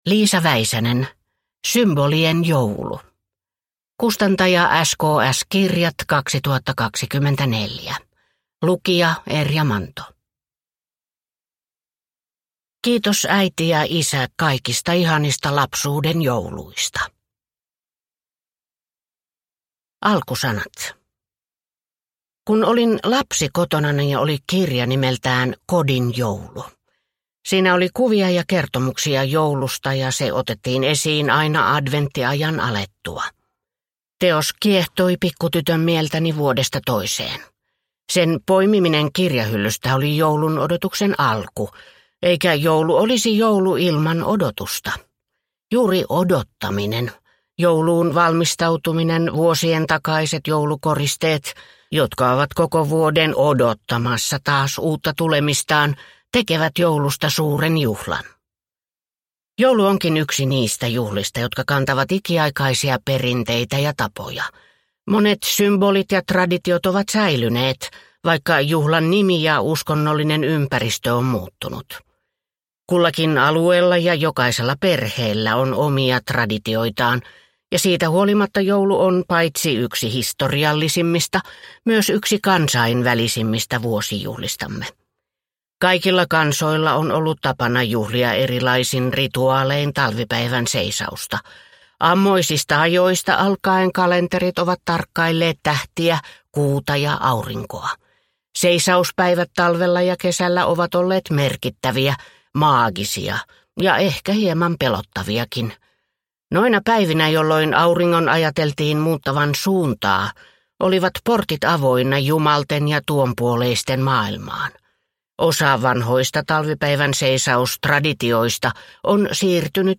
Symbolien joulu – Ljudbok